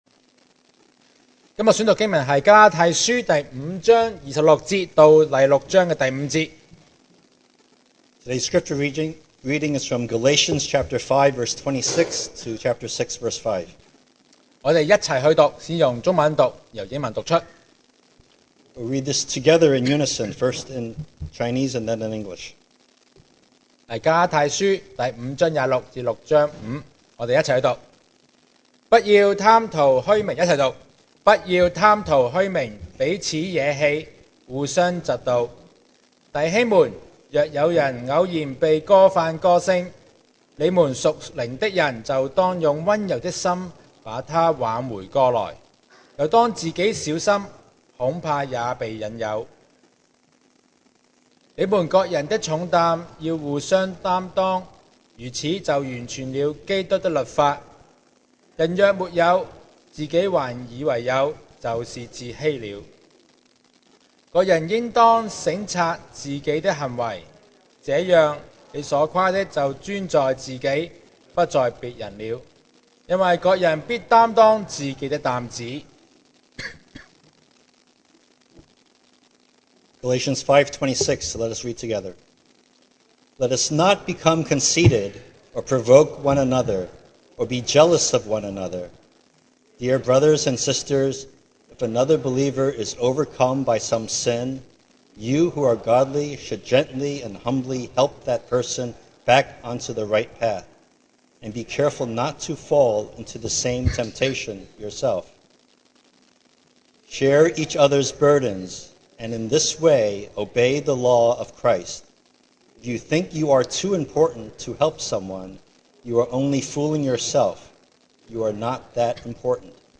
2023 sermon audios
Service Type: Sunday Morning